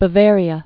(bə-vârē-ə)